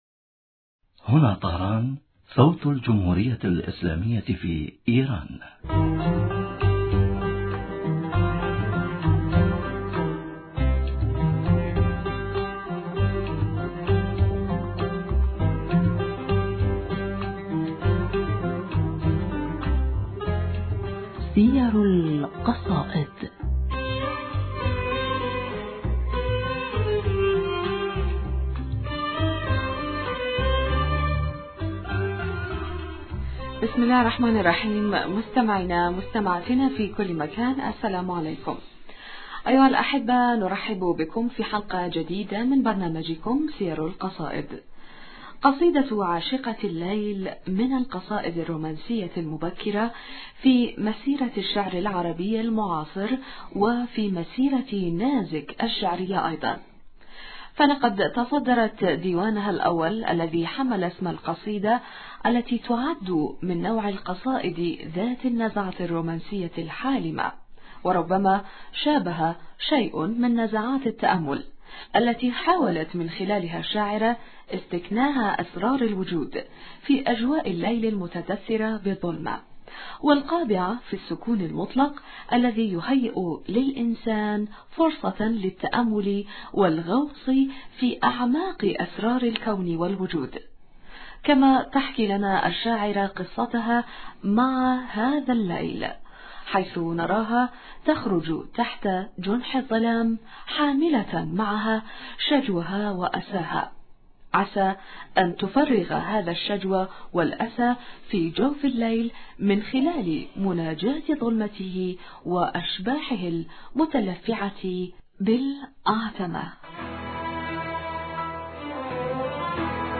معنا في الستوديو